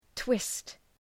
Προφορά
{twıst}